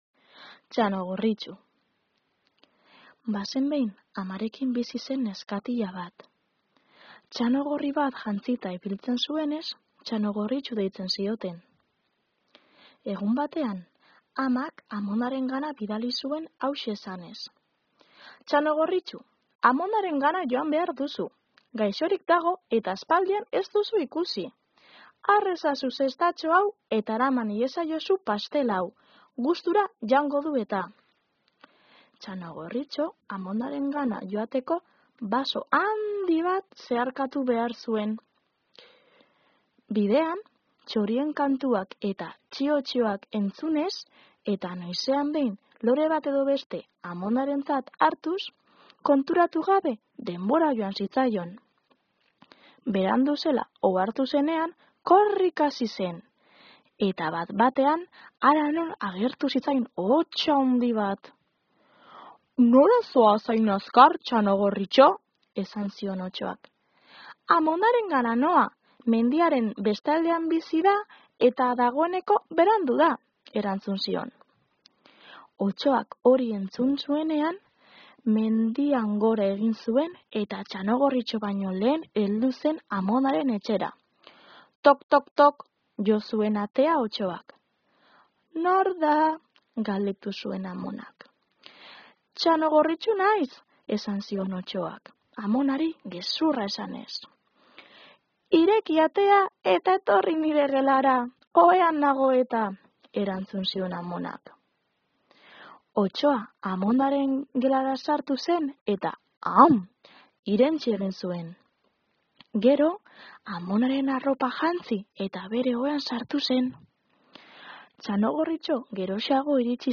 ipuin-kontaketa